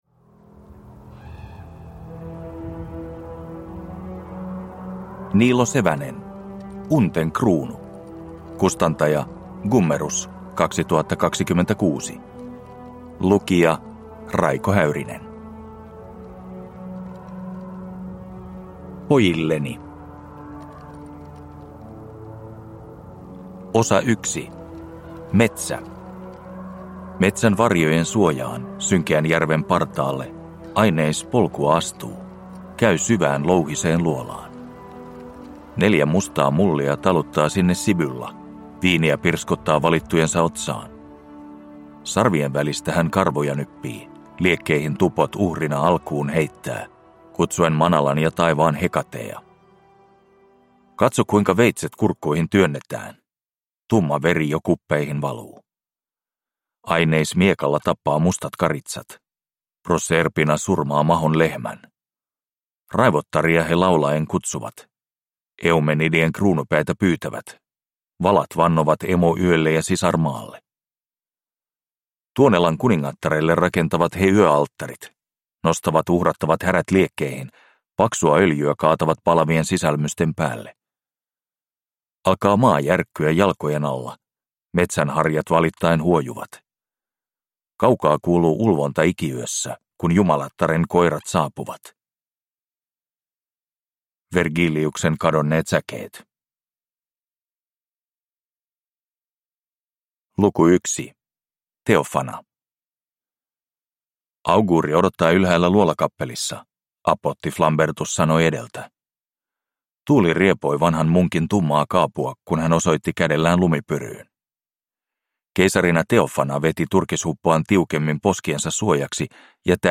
Unten kruunu – Ljudbok